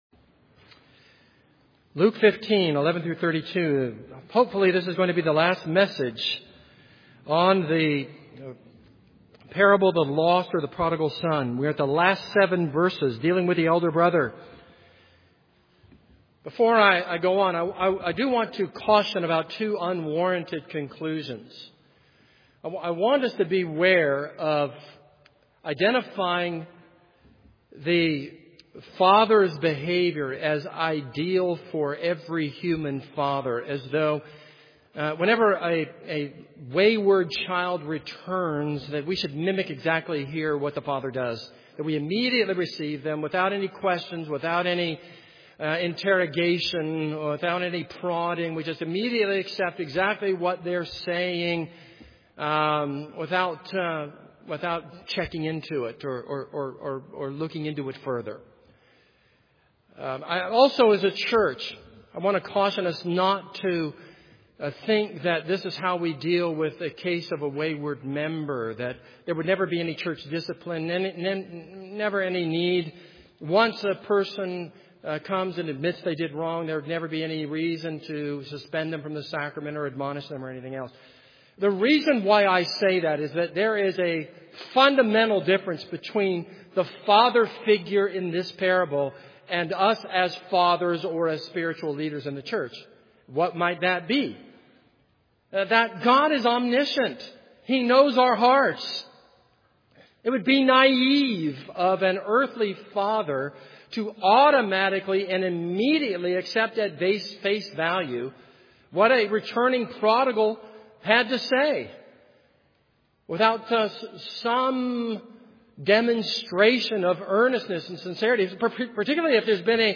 This is a sermon on Luke 15:11-32.